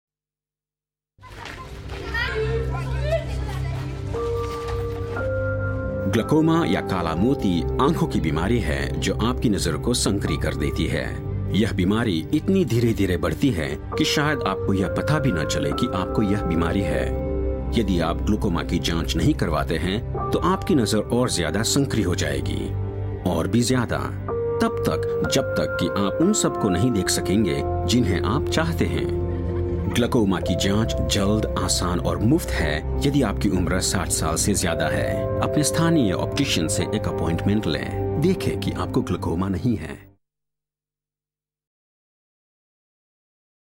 Asian, Hindi / Gujerati. Actor, versatile, accomplished.